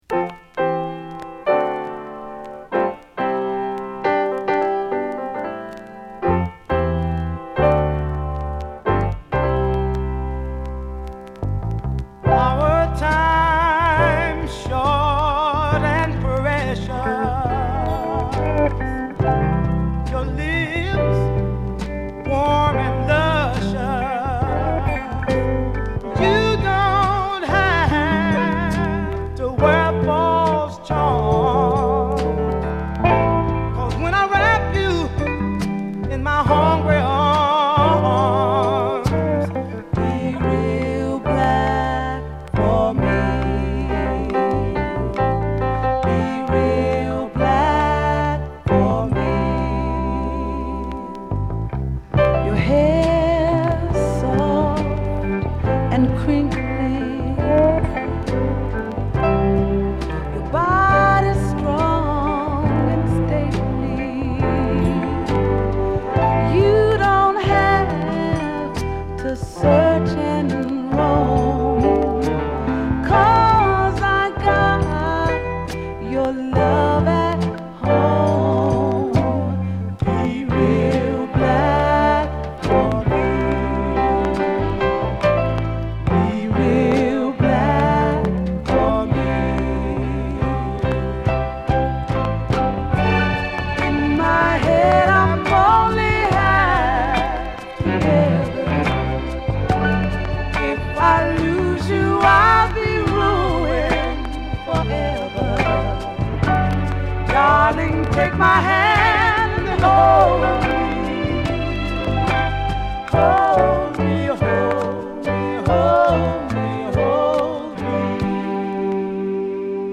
＊チリパチ多いです。